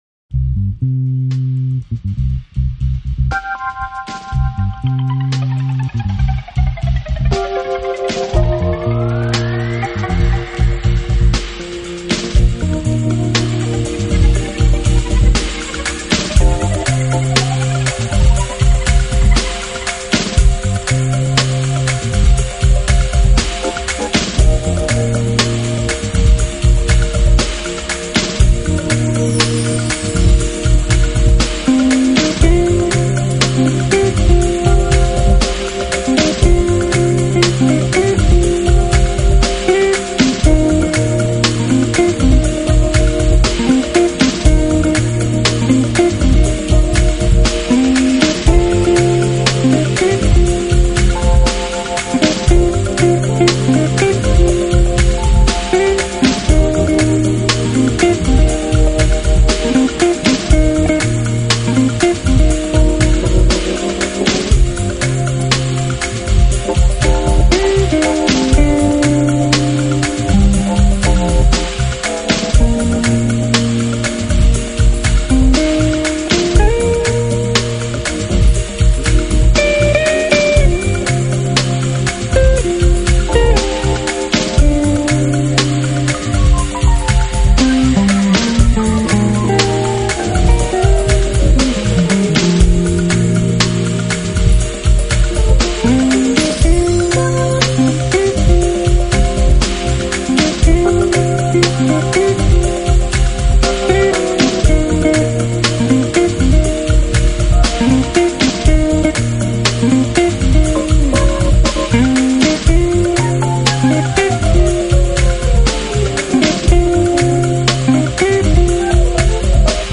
【爵士吉他】
音乐类型: smooth jazz